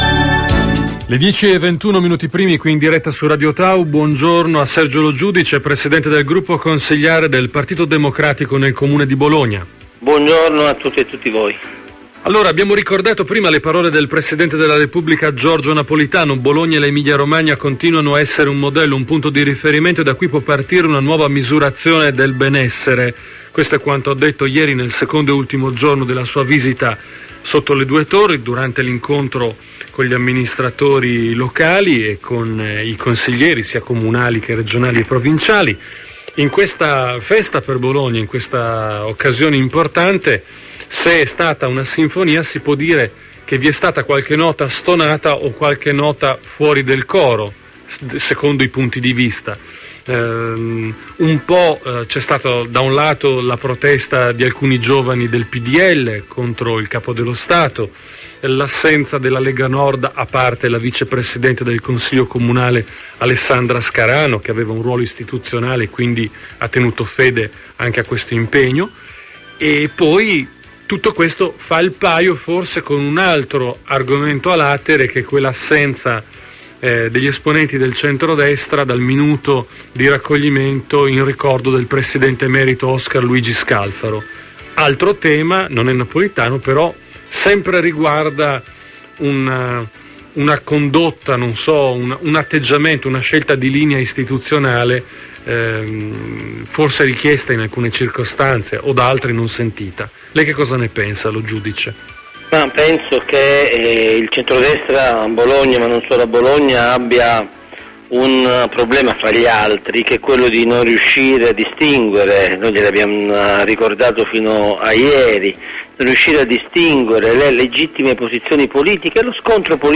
Intervista a Radio Tau del capogruppo PD Sergio Lo Giudice 1 febbraio 2012